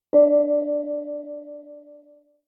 sunGong.ogg